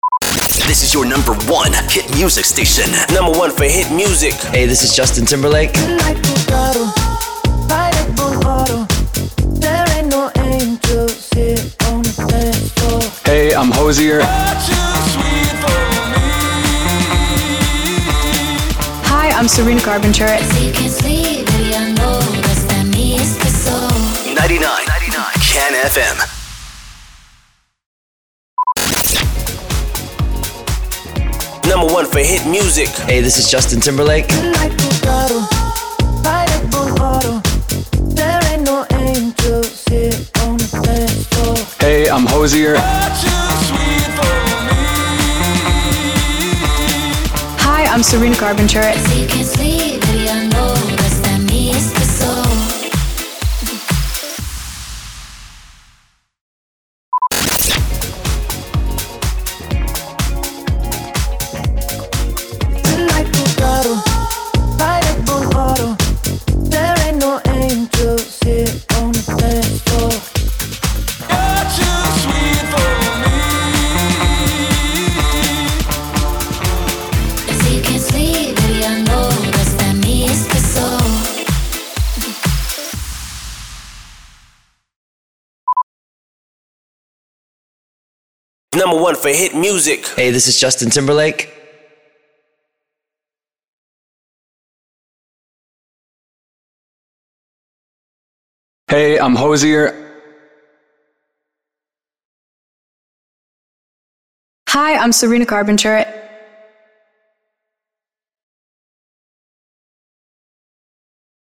612 – SWEEPER – BEATMIX PROMO
612-SWEEPER-BEATMIX-PROMO.mp3